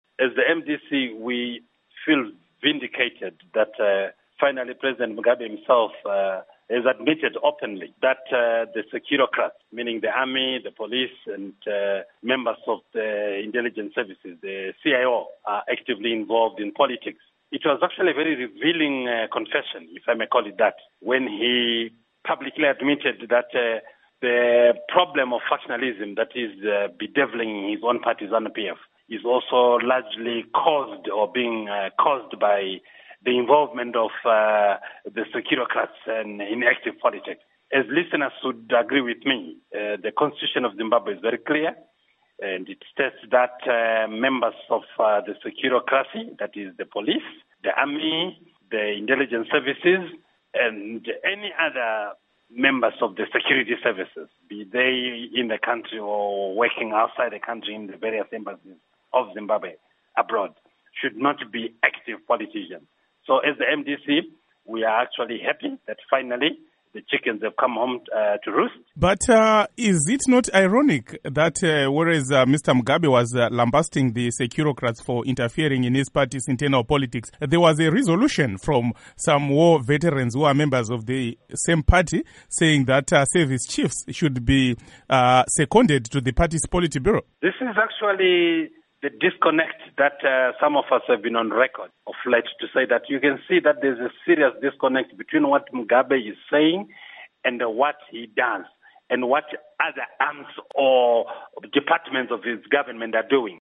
Interview With Obert Gutu Following Zanu PF Conference